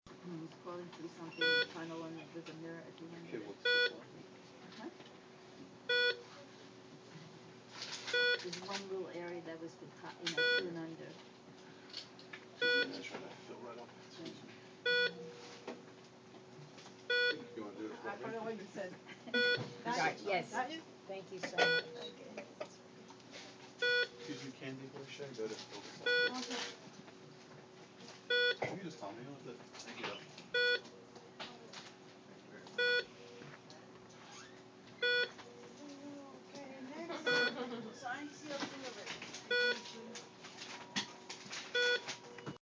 field recording
location: e.r. of St. Francis Hospital
sounds heard: people talking, machine beeping, papers shuffling, walking, laughter, someone putting a clipboard down on counter
sounds-of-e.r.4.mp3